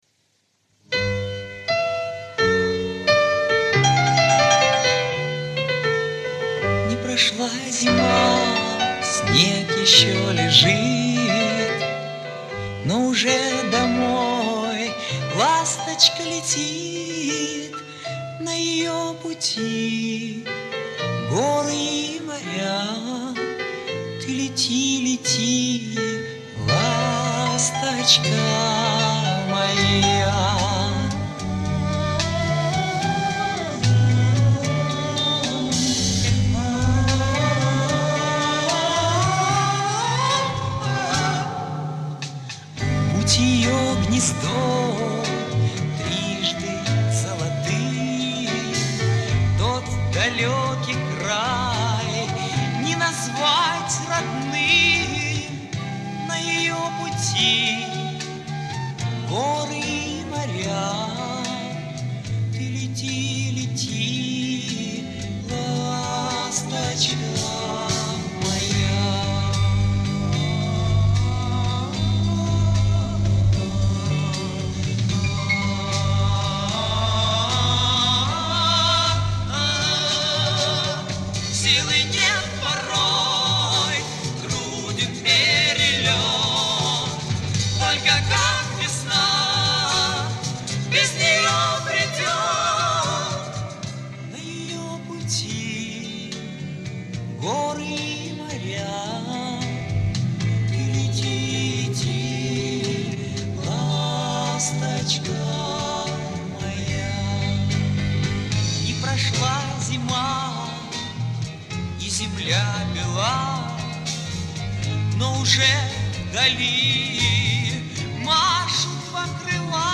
Убрал провалы.